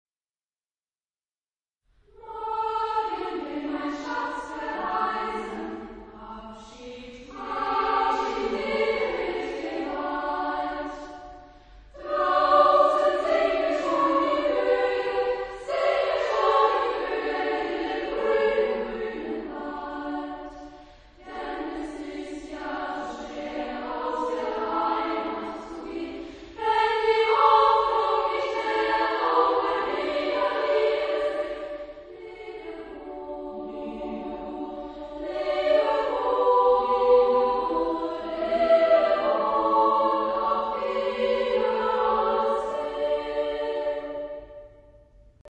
Epoque : 19ème s.
Genre-Style-Forme : Folklore ; Chanson ; Profane
Type de choeur : SSA  (3 voix égales de femmes )
Tonalité : ré majeur
Réf. discographique : 7. Deutscher Chorwettbewerb 2006 Kiel